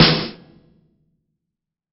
SNARE 085.wav